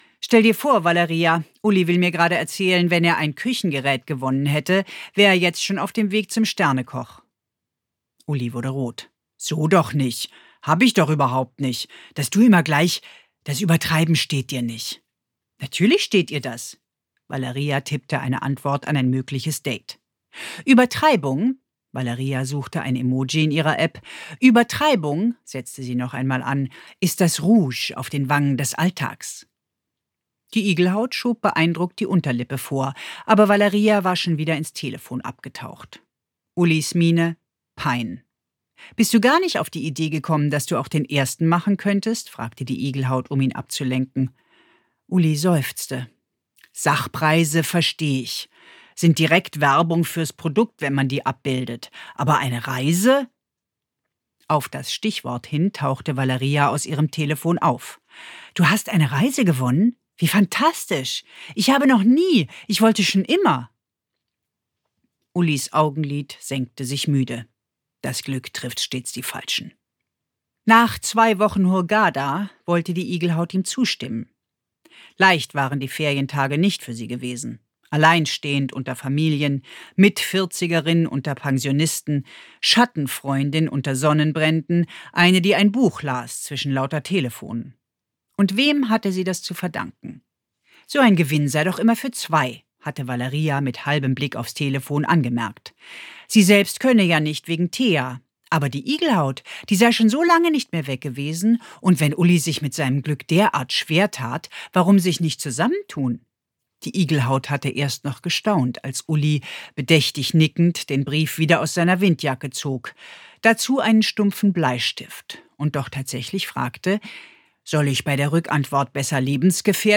Anna Schudt (Sprecher)
Ungekürzte Lesung